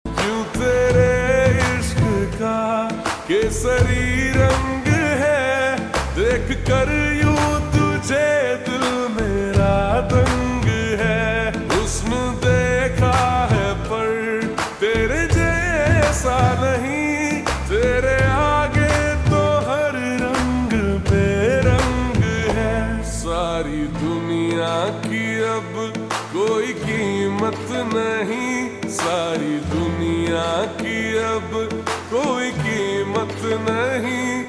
Hindi Songs
Slow Reverb Version
• Simple and Lofi sound
• Crisp and clear sound